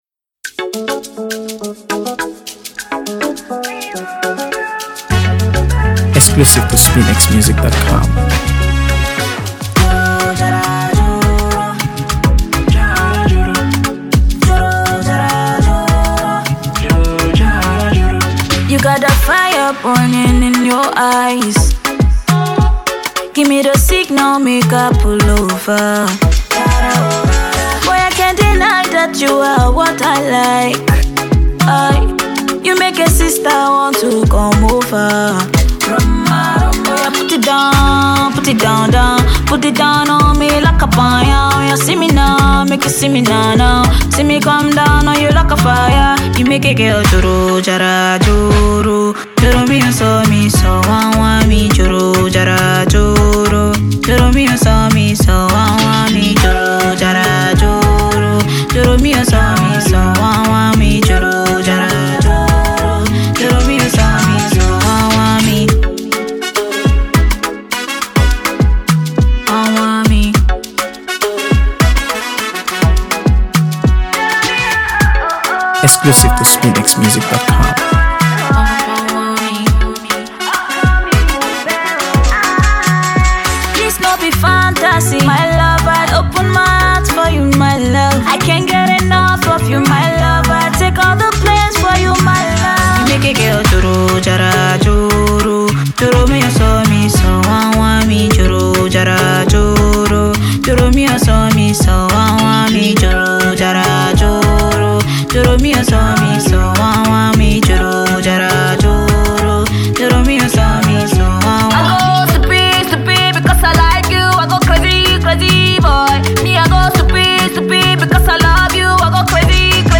AfroBeats | AfroBeats songs
a delightful tune that leaves listeners wanting more.